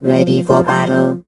rick_start_vo_02.ogg